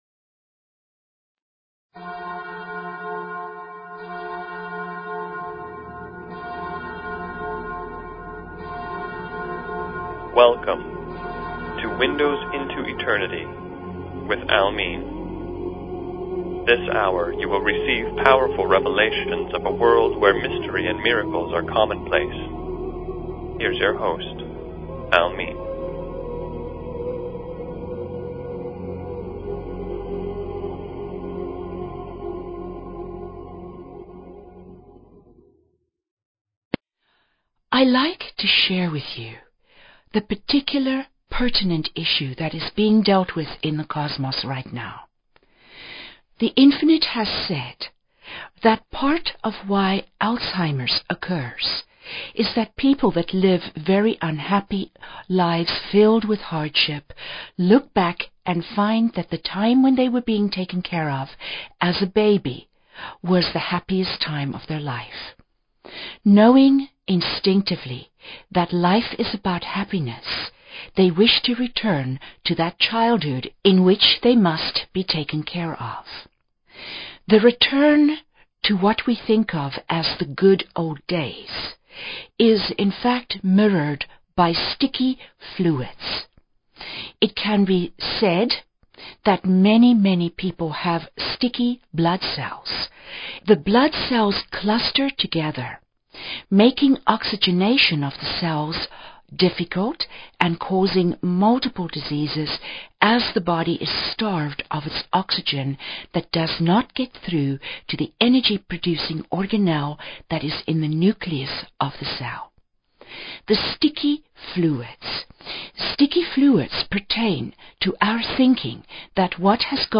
Talk Show Episode, Audio Podcast, Windows_Into_Eternity and Courtesy of BBS Radio on , show guests , about , categorized as
Guests from our international Light family join us; learn about Belvaspata in Russia.